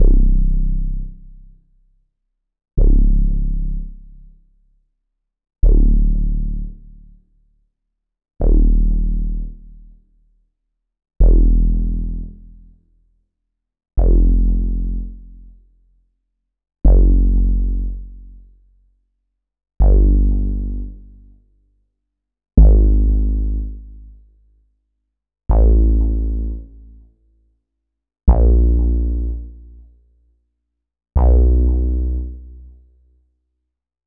M Elec Bass.wav